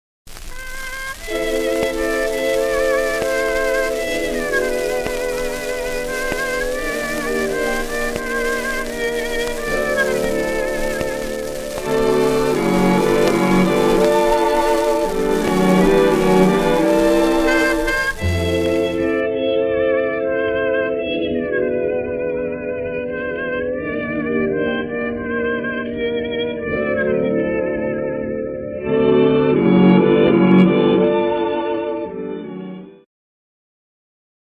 DEMO - Restoration of 78 RPM Record (Before & After)
DEMORESTORATION78RPMRECORD(BEFOREANDAFTER).mp3